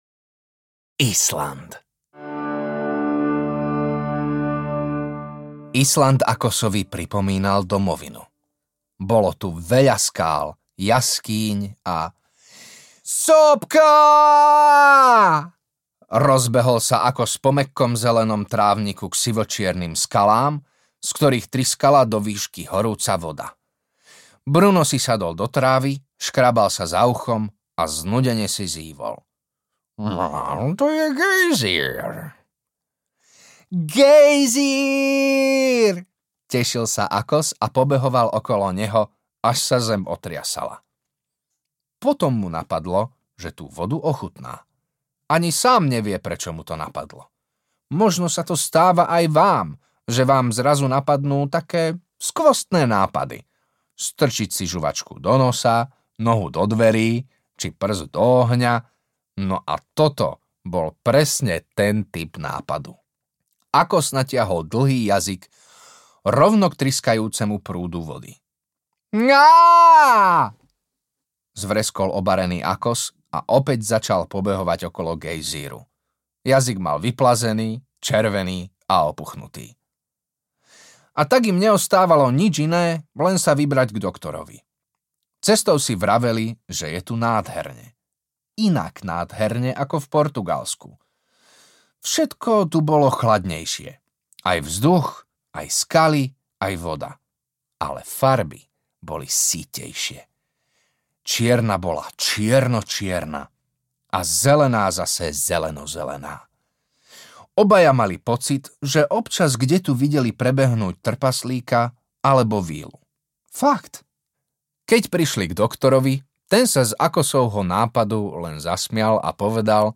Akos Avoláš audiokniha
Ukázka z knihy